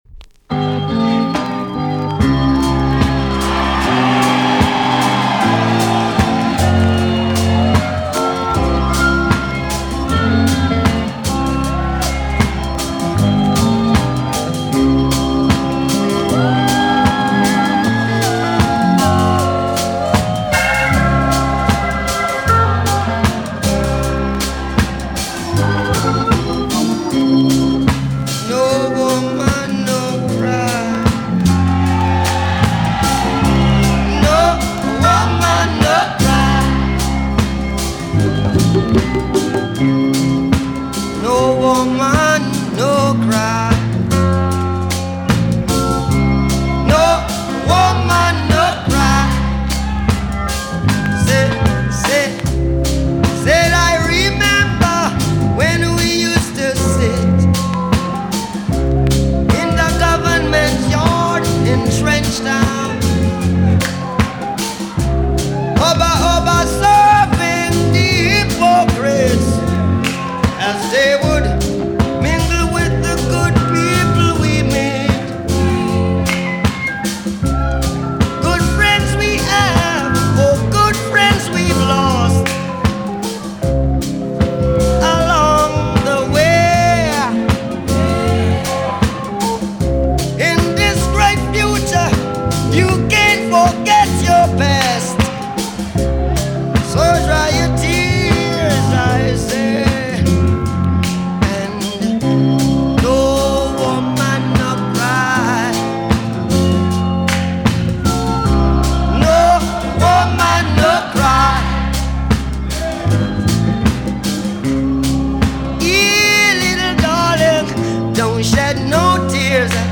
TOP >REGGAE & ROOTS
EX-~VG+ 少し軽いチリノイズが入りますが良好です。
ロンドンのライシアムシアターでの貴重なコンサートのライブ音源を収録!!